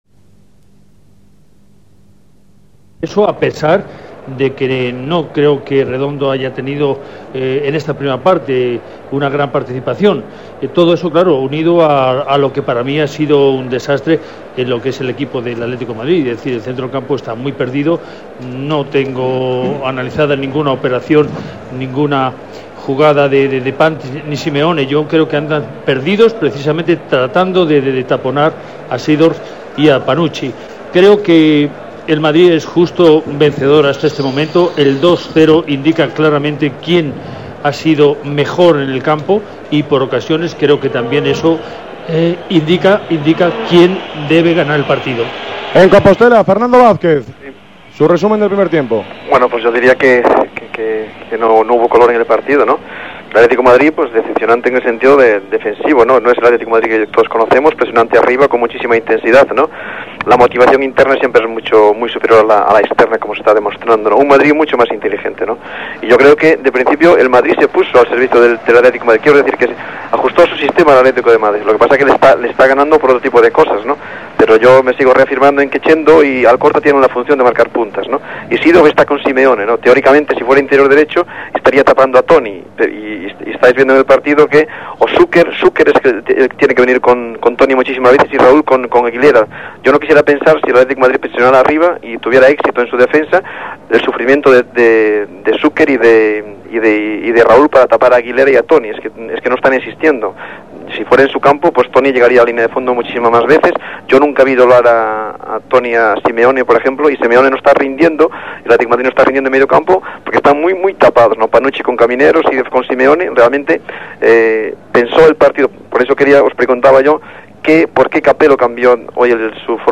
El partido contó con los comentarios de un gran número de expertos como Jorge D’Alessandro, Paco Gento, Álvaro Benito Villar, Manolo Velázquez, Fernando Vázquez, Santillana, Miquel Soler. Durante el descanso del partido se pueden escuchar las impresiones de Joan Gaspart, Lorenzo Sanz y Jesús Gil.